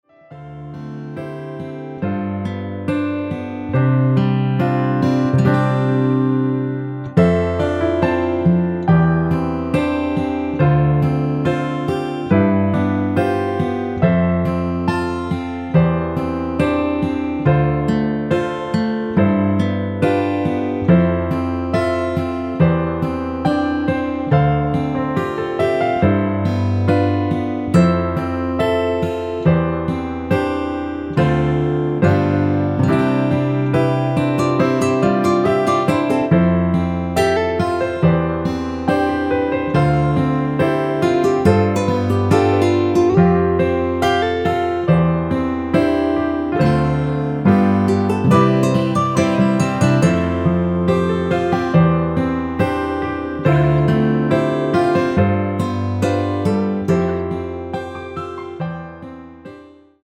(+2) 멜로디 포함된1절후 후렴으로 진행되게 편곡 하였습니다.(아래의 가사 참조)
멜로디 MR이란
노래방에서 노래를 부르실때 노래 부분에 가이드 멜로디가 따라 나와서
앞부분30초, 뒷부분30초씩 편집해서 올려 드리고 있습니다.
중간에 음이 끈어지고 다시 나오는 이유는